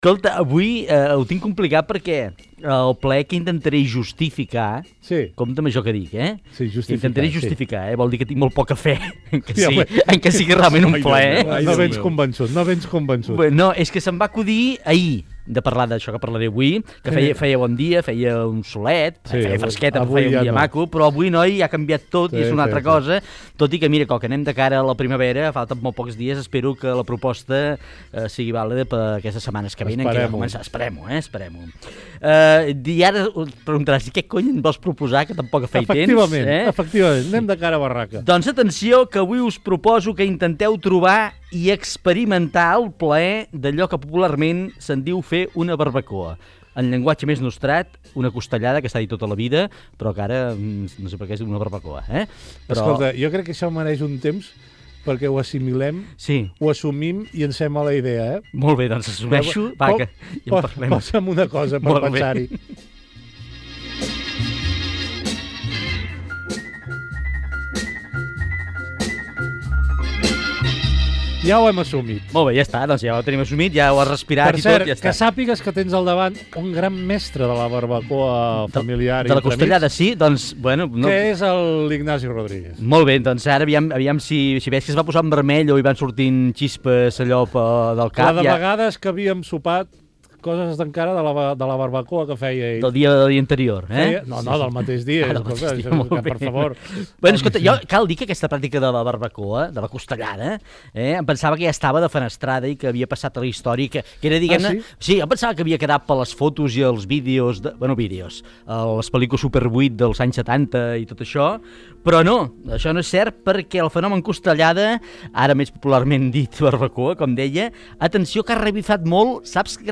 Diàleg sobre barbacoes i costellades Gènere radiofònic Entreteniment
Fragment extret de l'arxiu sonor de COM Ràdio.